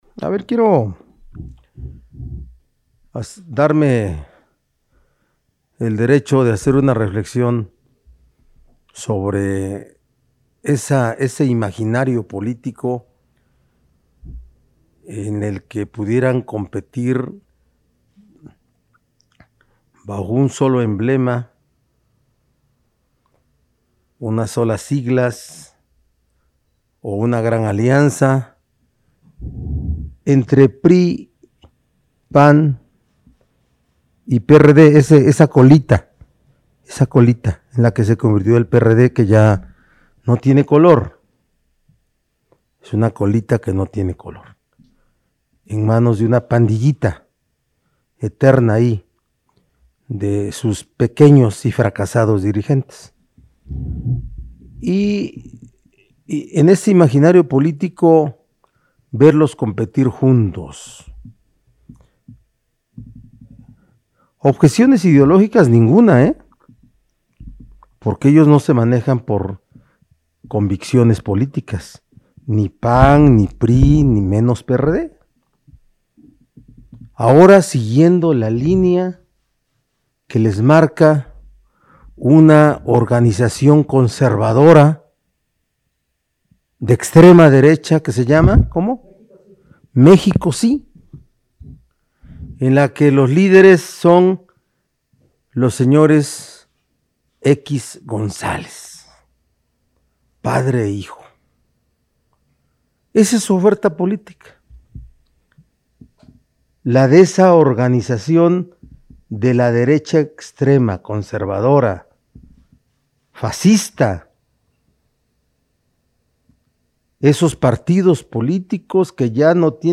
En la habitual videoconferencia de prensa que preside en Casa Aguayo, el mandatario estatal señaló que, debido a que carecen de convicciones y valor ideológico, ninguno de los tres partidos políticos tendría objeciones de unirse bajo una línea que ahora les marca dicha organización conservadora, fascista y de extrema derecha.